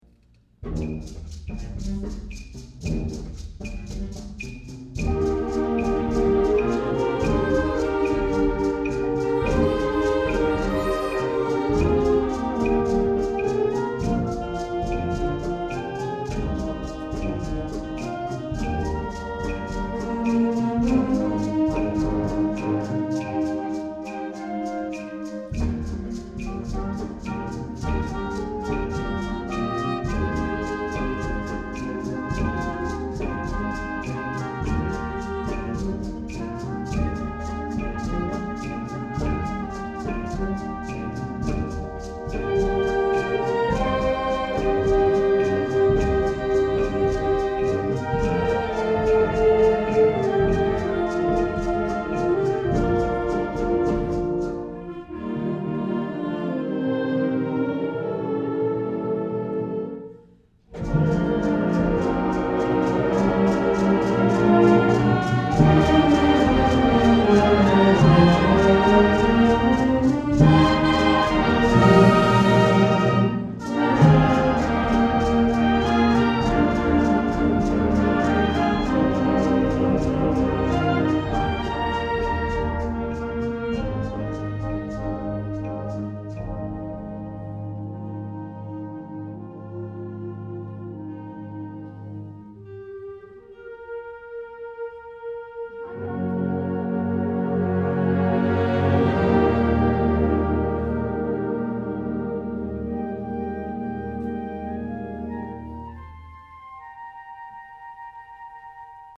2008 Winter Concert
December 14, 2008 - San Marcos High School